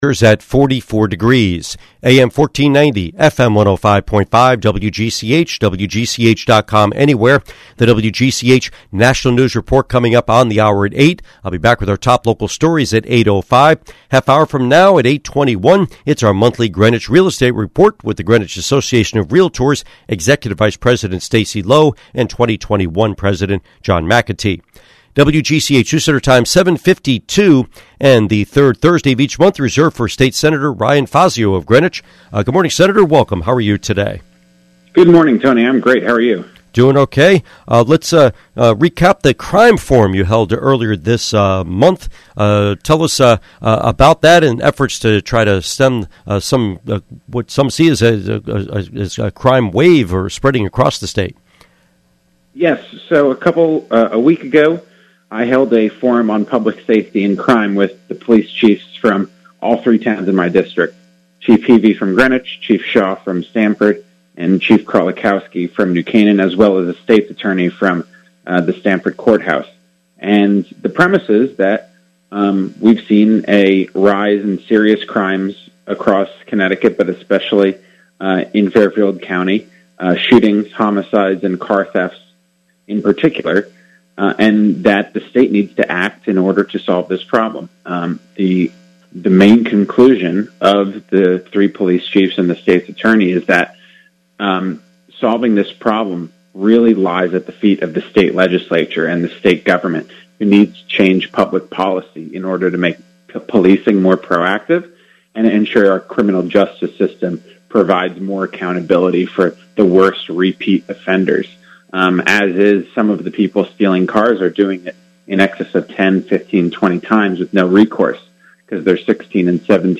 Interview with State Senator Fazio